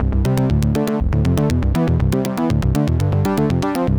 Rich 5th Lead.wav